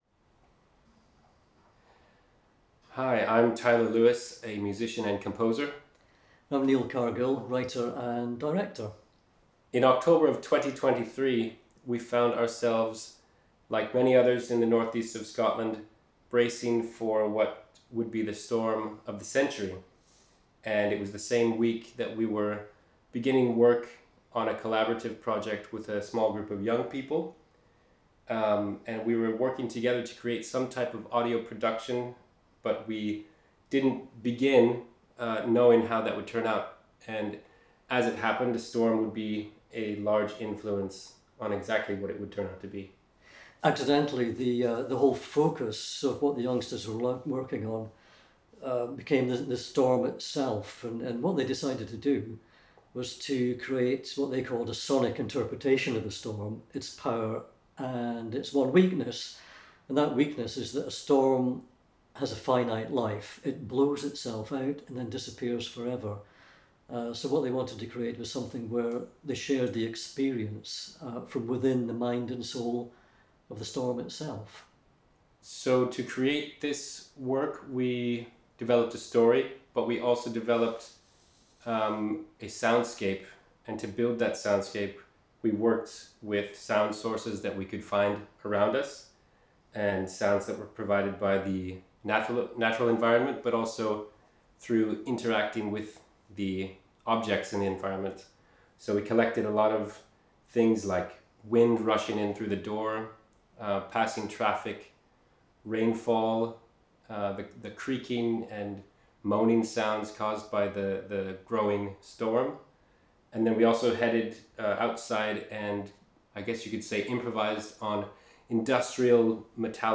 A sonic interpretation of a storm, its power, and...